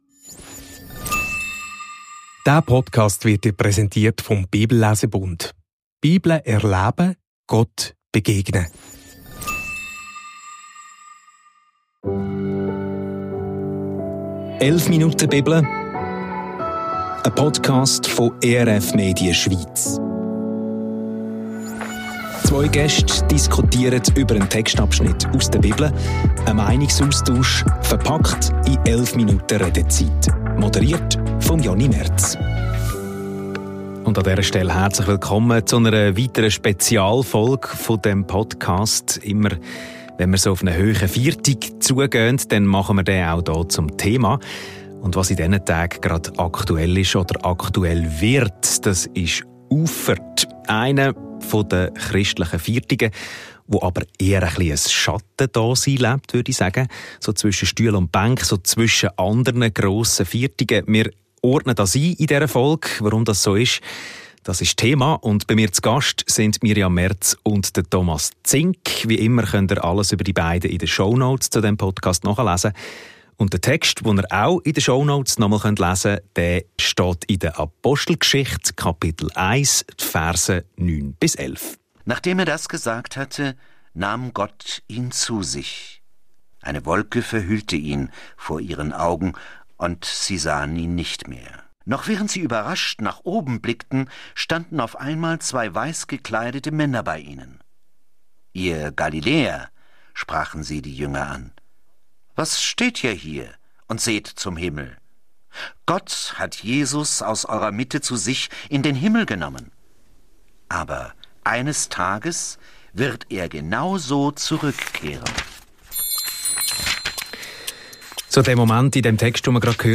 Auffahrt schafft Platz für etwas Neues – Apostelgeschichte 1,9–11 ~ 11 Minuten Bibel – ein Meinungsaustausch Podcast